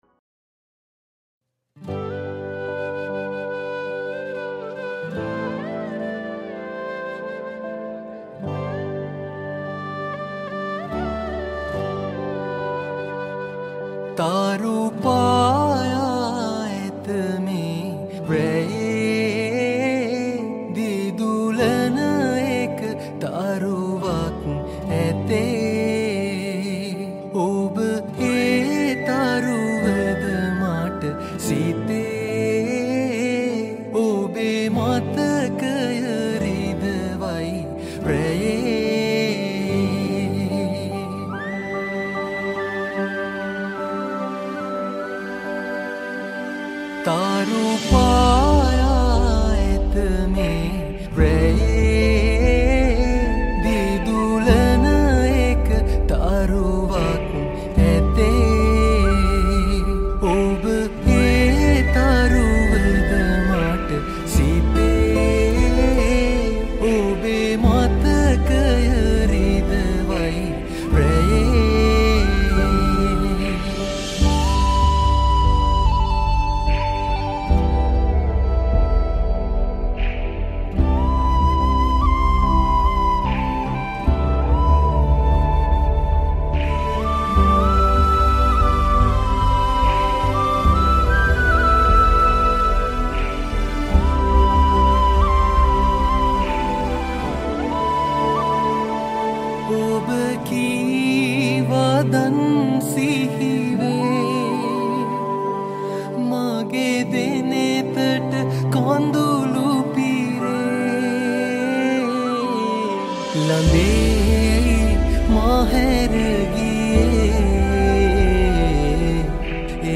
Guitars
Flutes